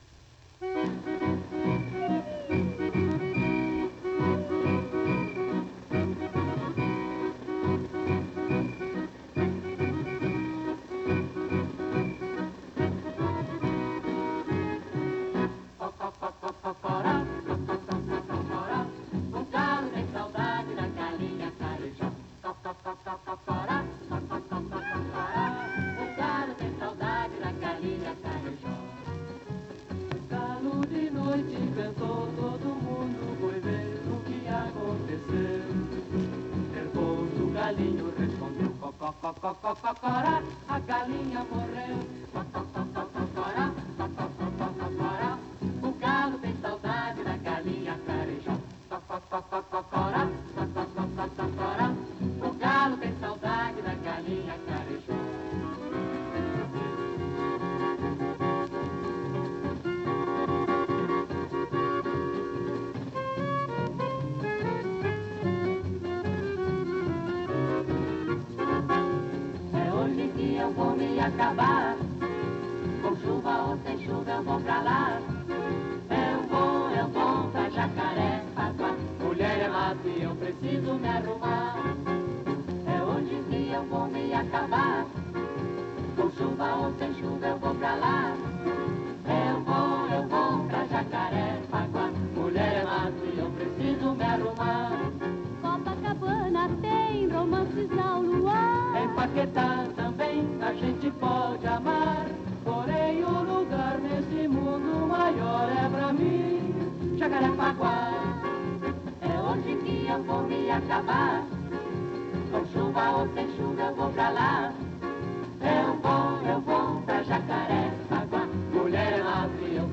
Попурри на темы бразильских карнавальных песен.